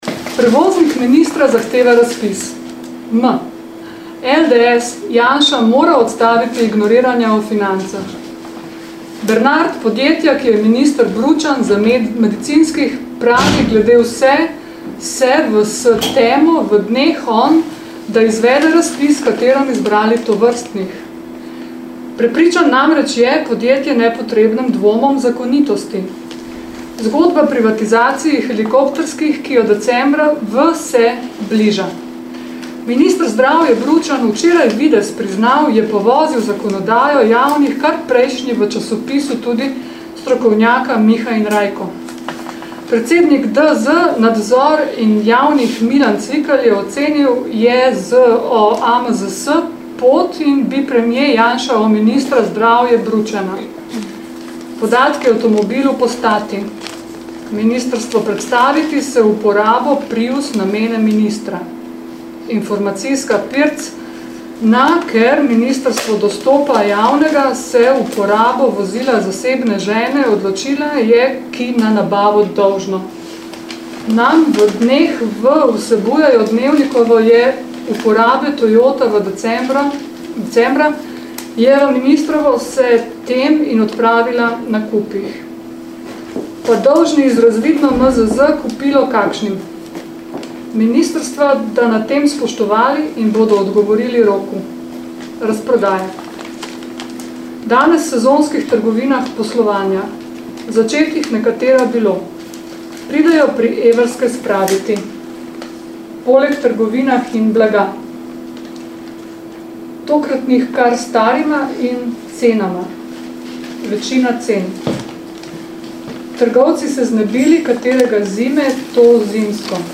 First public encounter with the publication the Ultimate Word December 8, at 19.30 Visitors were seated in a circle and invited to participate in a reading of the Ultimate Word . Each person read for two minutes (three minutes were suggested, but this proved to be 'too long'); the word 'forward' was the sign for the next person to take over. An audio recording of the reading of the Ultimate Word .
The reading, which lasted 23'52'', created several impressions in my mind: from the cacophony of words, letters, sounds, meaning, stuttering and pauses, to the euphony of 'liberated' words.The noise created by the synchronised leafing of the newspaper the Ultimate Word , the rich rustling of freshly printed paper.